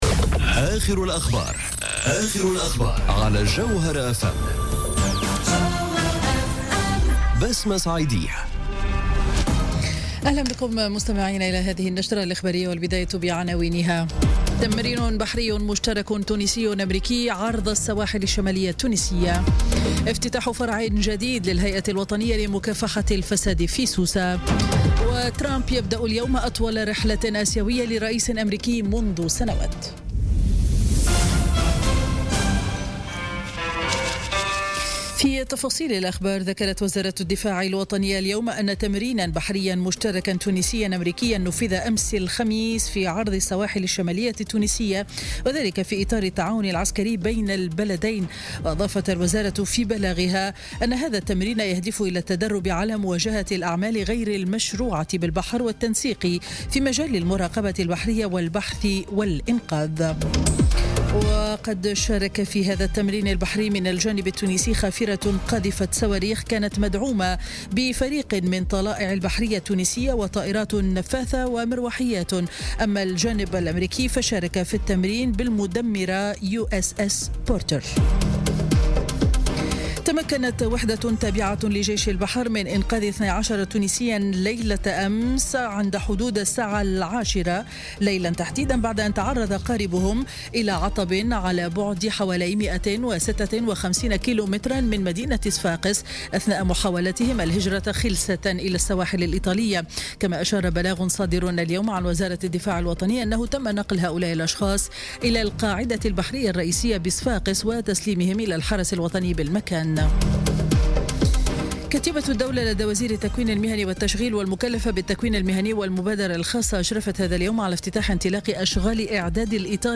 نشرة أخبار منتصف النهار ليوم الجمعة 03 نوفمبر 2017